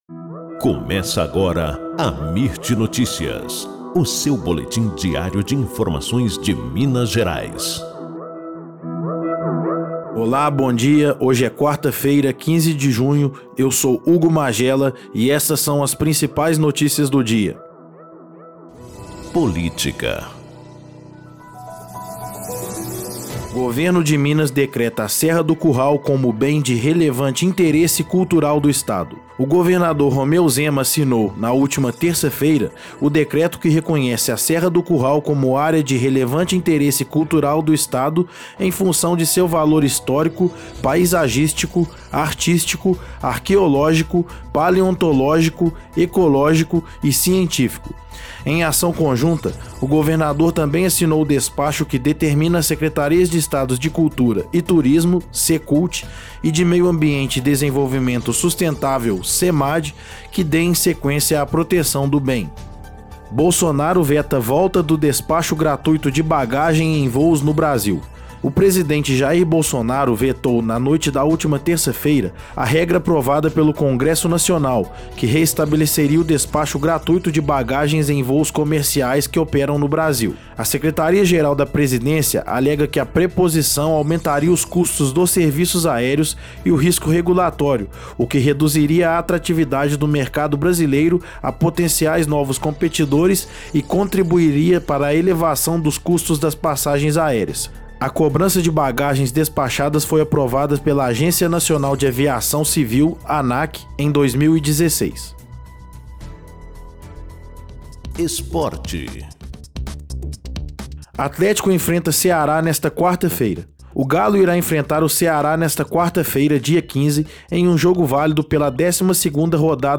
Boletim Amirt Notícias – 15 de junho